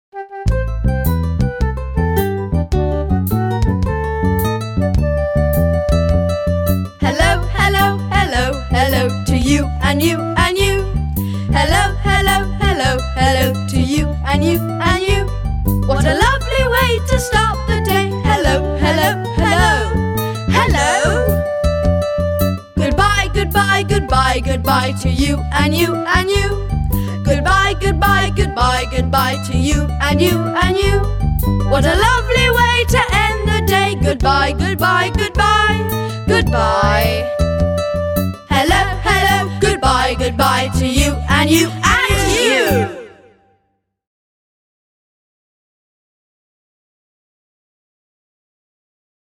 Chant : Hello, goodbye 2.